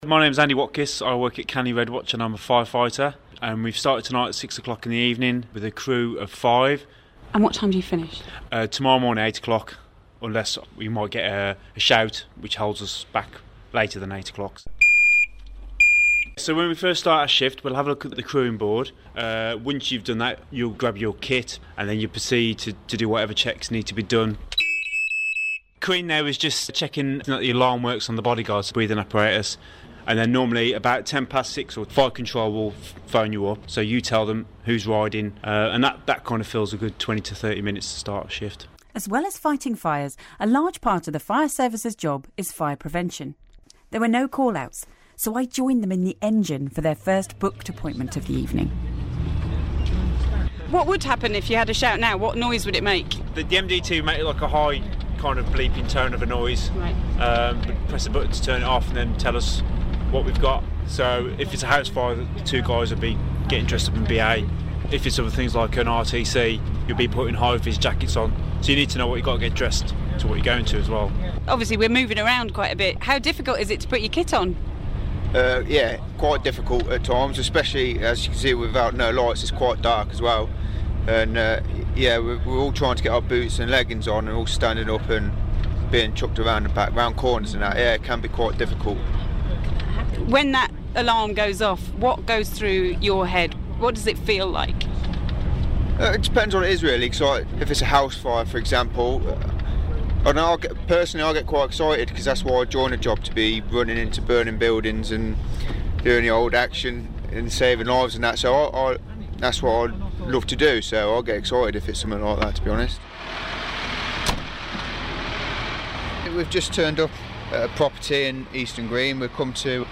As part of BBC Coventry & Warwickshire's After Dark programmes, we joined Canley Red Watch (West Midlands Fire Service) for a typical night shift.